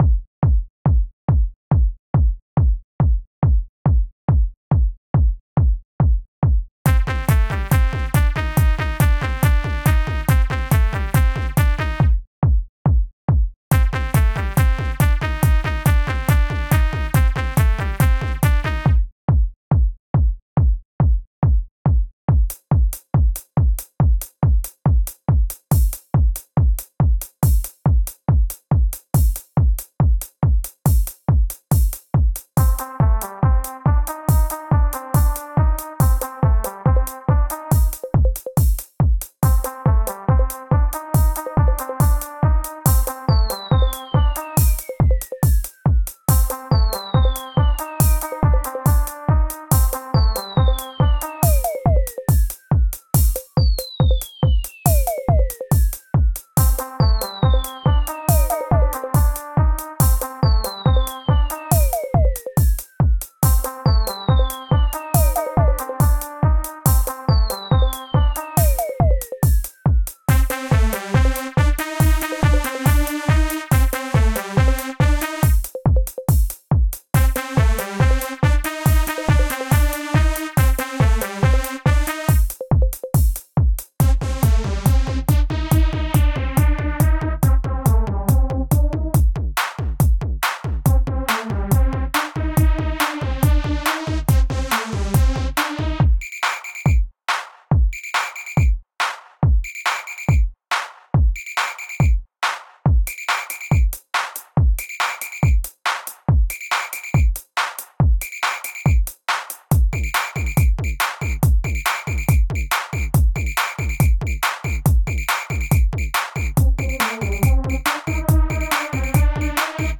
Pieza de Electroclash
Música electrónica
melodía
sintetizador